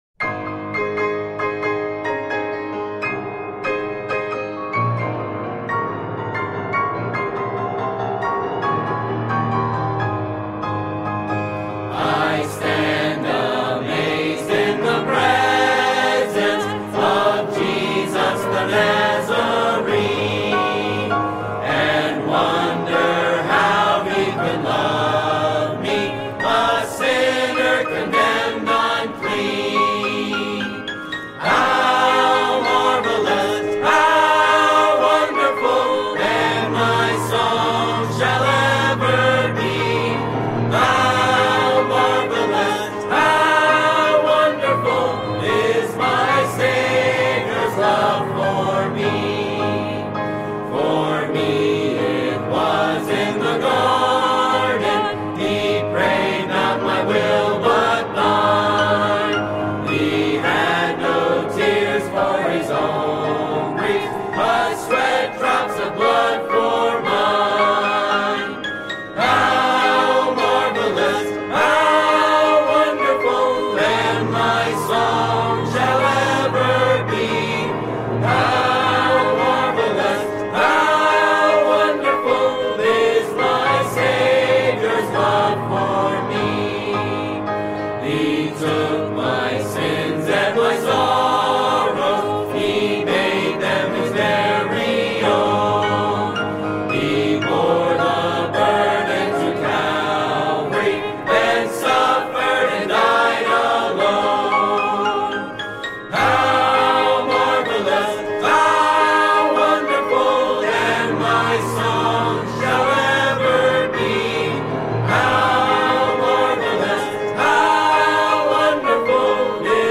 Music Hymns